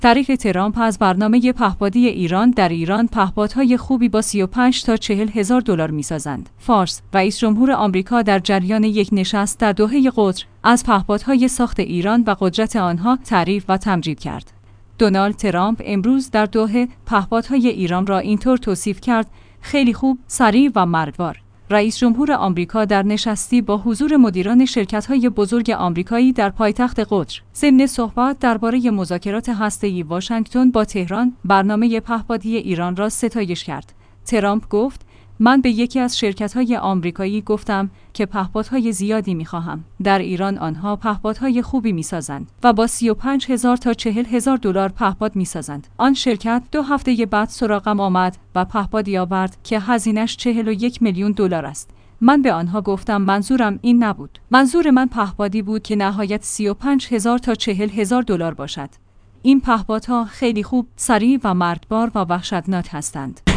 فارس/ رئیس‌جمهور آمریکا در جریان یک نشست در دوحه قطر، از پهپادهای ساخت ایران و قدرت آنها تعریف و تمجید کرد.